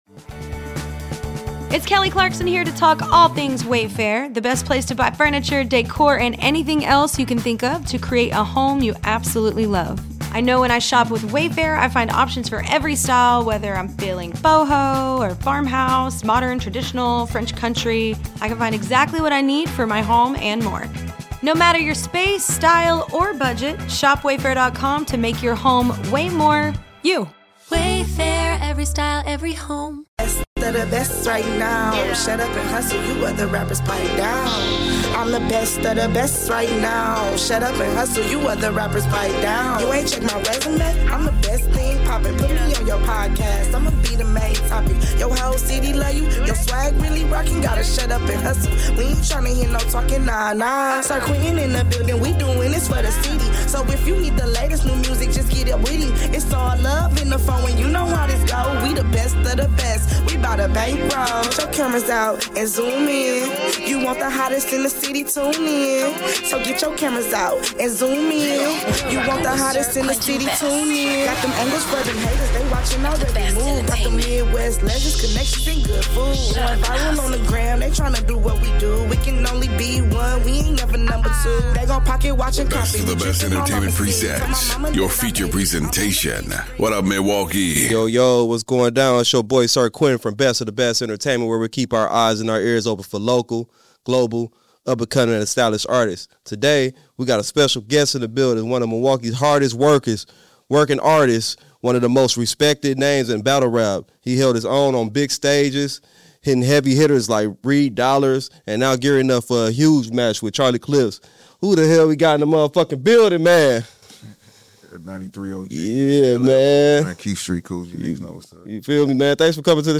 Interviewing Local, Global and up and coming talent.